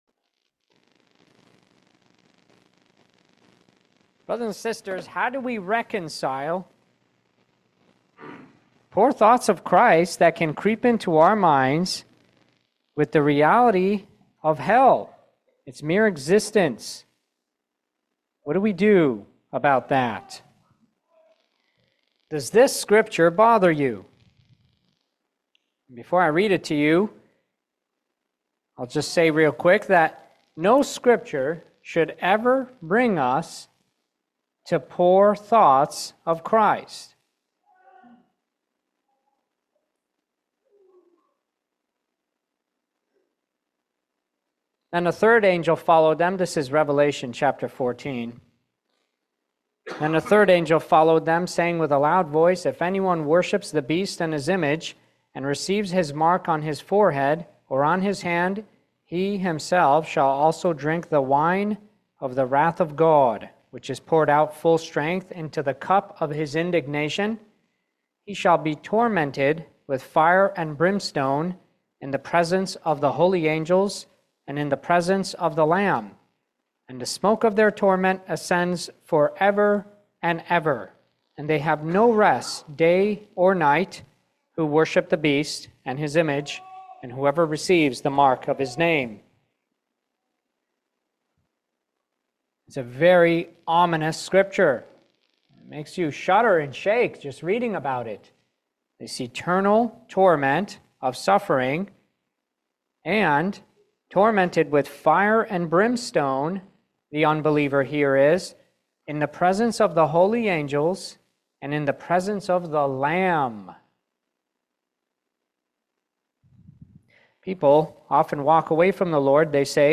Service Type: Special Meeting